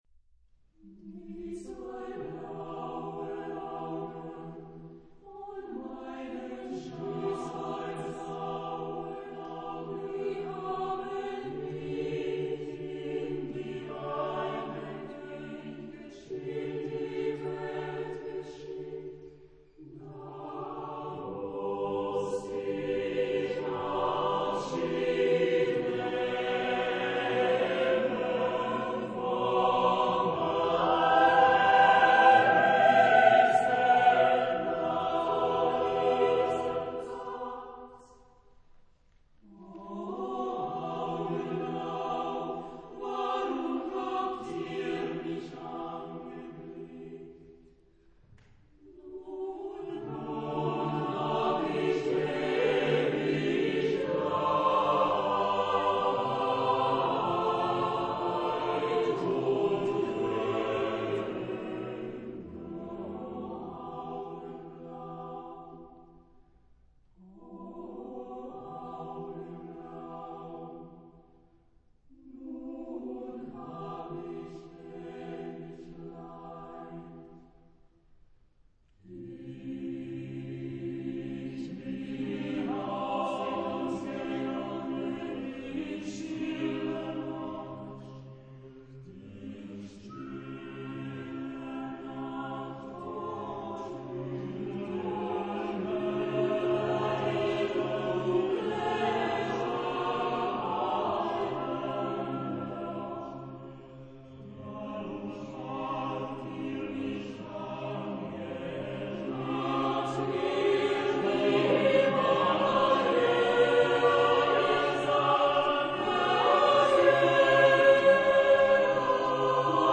Genre-Style-Forme : Profane ; Romantique
Caractère de la pièce : mystérieux ; mélancolique
Type de choeur : SATB + SATB + SATB + SATB  (16 voix mixtes OU Quadruple chœur )
Réf. discographique : Internationaler Kammerchor Wettbewerb Marktoberdorf